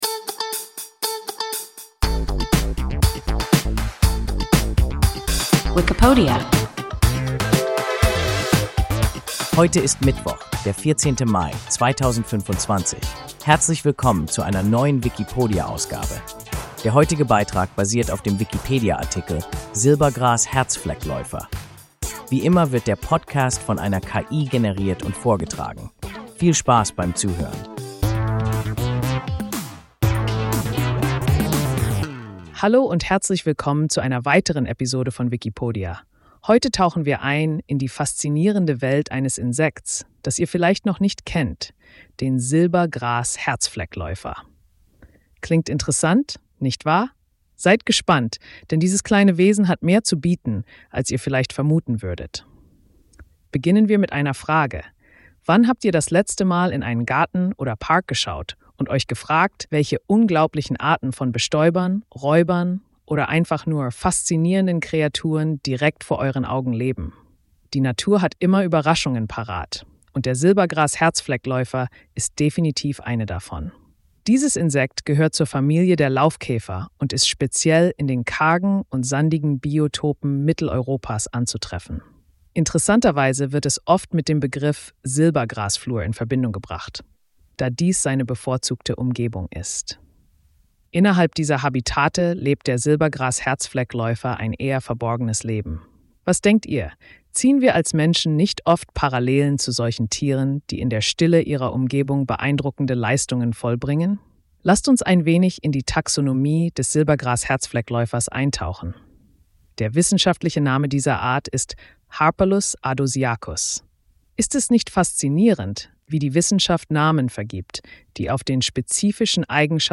Silbergras-Herzfleckläufer – WIKIPODIA – ein KI Podcast